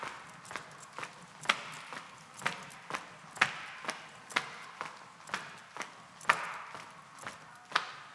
mixkit-footsteps-in-a-tunnel-loop-543.wav